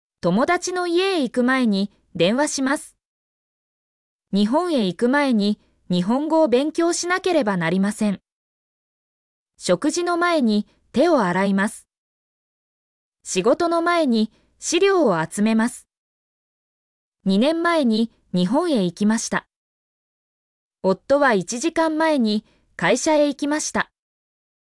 mp3-output-ttsfreedotcom-32_QQSH1E6b.mp3